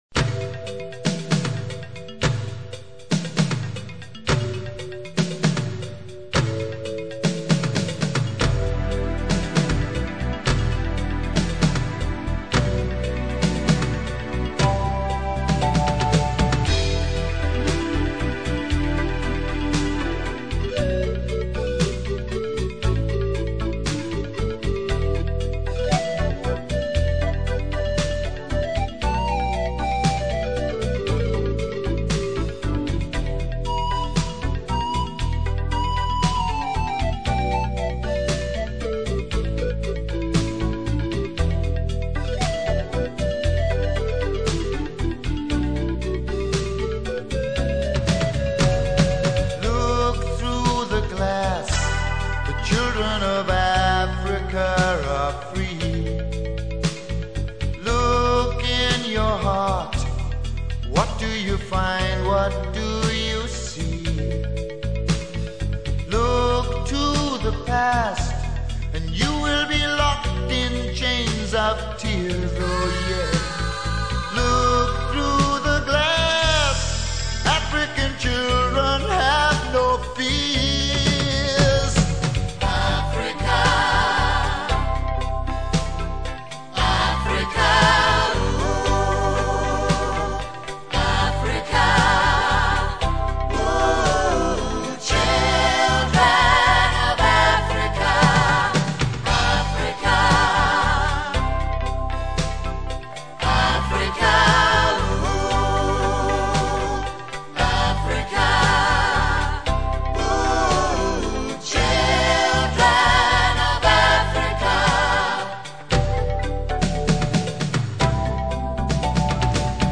Lead Vocals
Guitar
Kbds